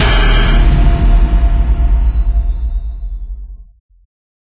Magic12.ogg